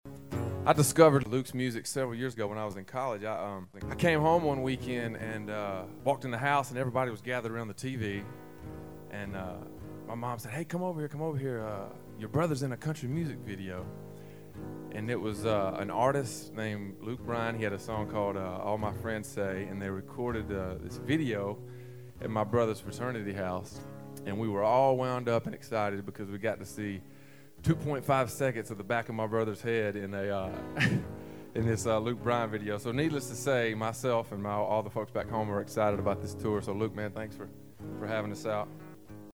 Audio / Sam Hunt recalls his first connection to Luke Bryan, which involved his brother.